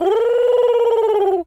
Animal_Impersonations
pigeon_call_angry_13.wav